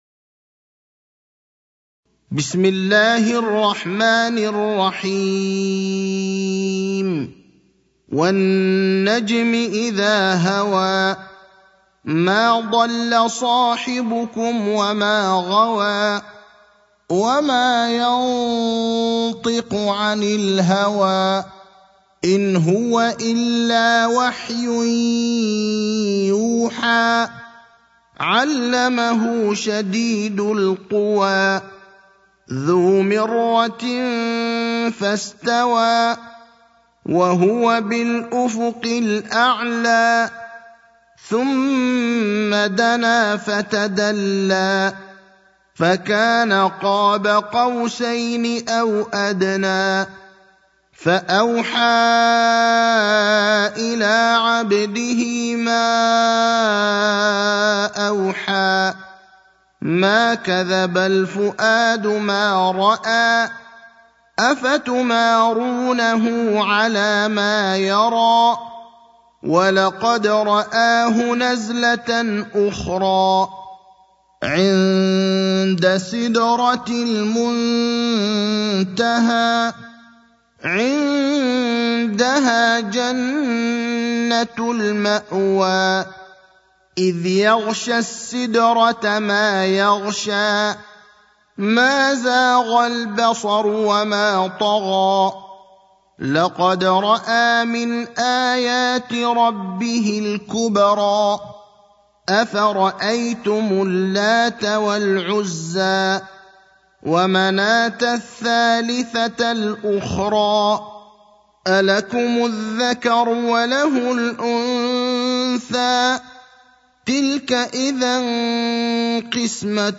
المكان: المسجد النبوي الشيخ: فضيلة الشيخ إبراهيم الأخضر فضيلة الشيخ إبراهيم الأخضر النجم (53)